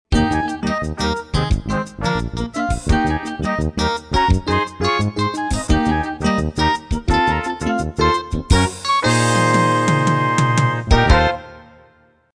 Puts me in a good mood for the day.